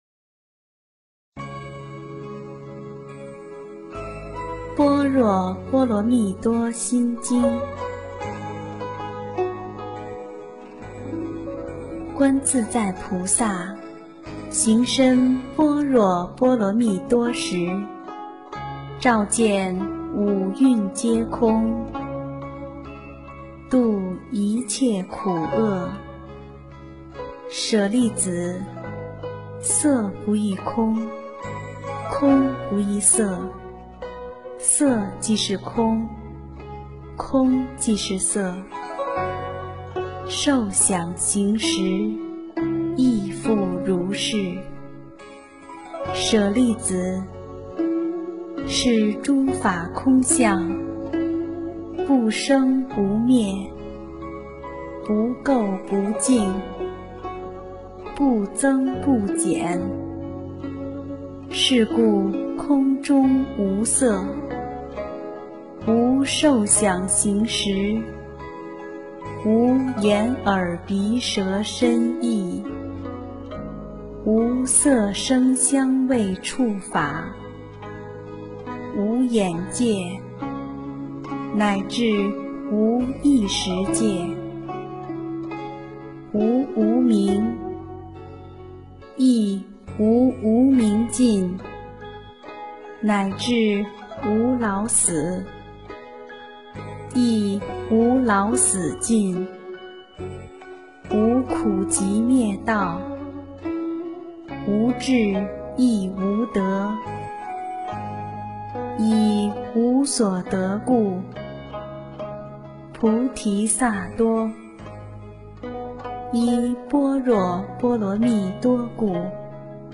《心经》英文·最美大字拼音经文教念